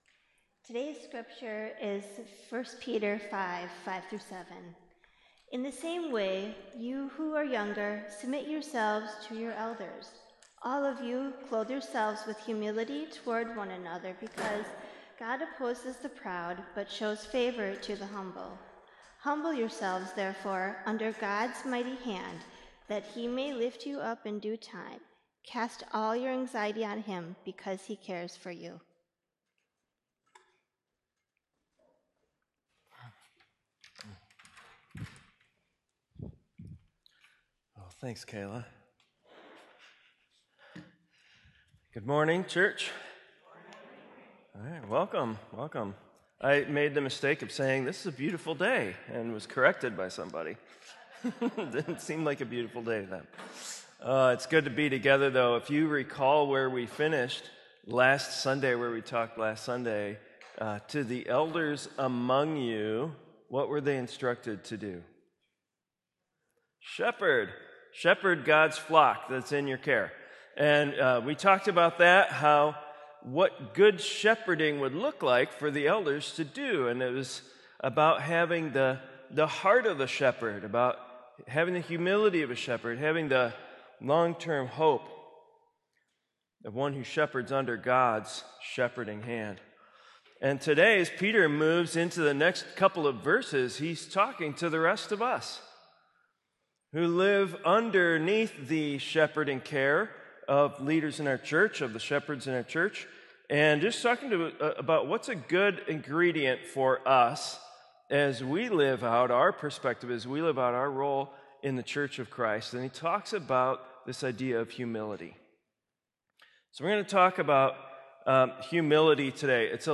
sermon-1-peter-humility.m4a